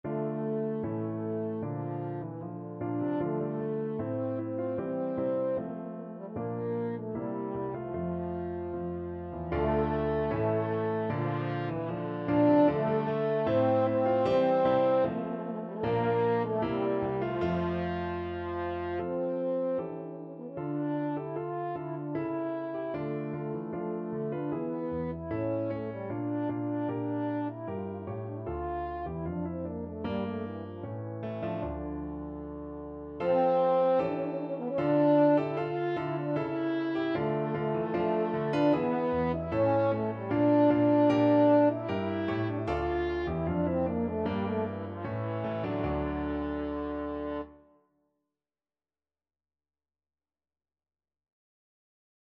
2/2 (View more 2/2 Music)
Steadily =c.76
Classical (View more Classical French Horn Music)